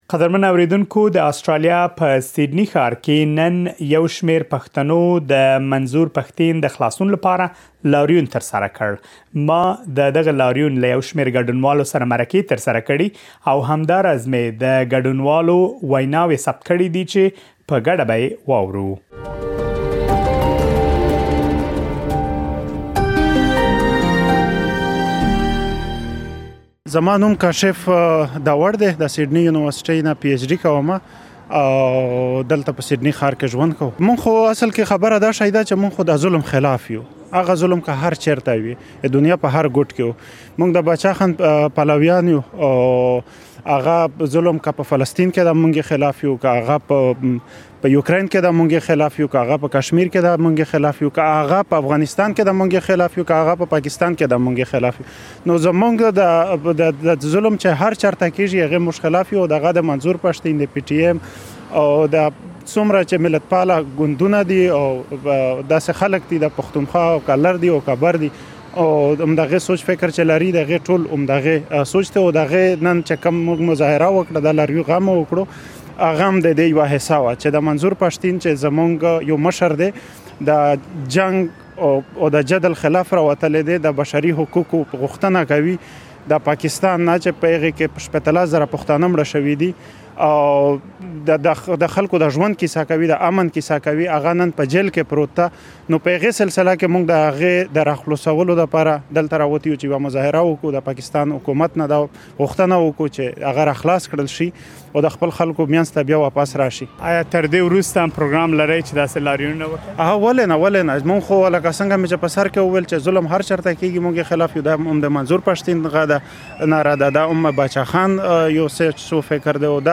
د لاریون په اړه د لا ډېرو جزیاتو لپاره مهرباني وکړئ رپوټ‌ واورئ.